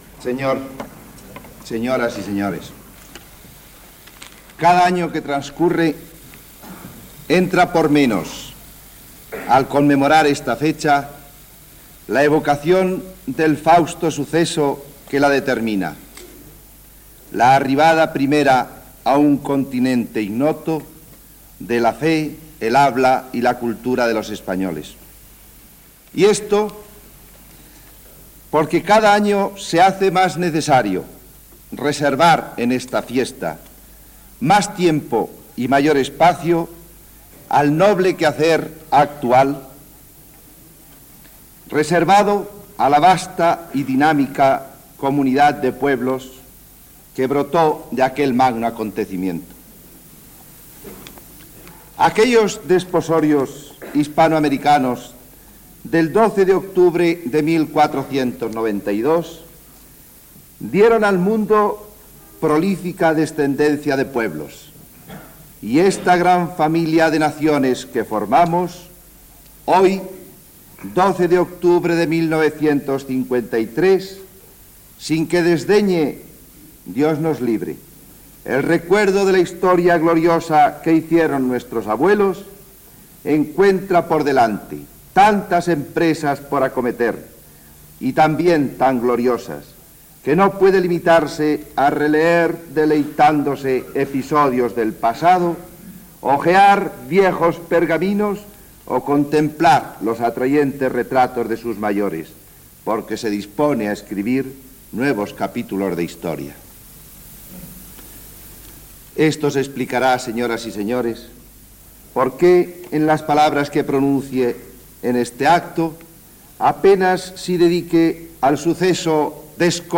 Transmissió de l'acte de celebració del Día de la Hispanidad, des de la seu del Centro Superior de Investigaciones Científicas. En la sessió acadèmica presidida pel cap d'Estat Francisco Franco, fa un discurs Alberto Martín Artajo, Ministro de Asuntos Exteriores i president de l'Instituto de Cultura Hispánica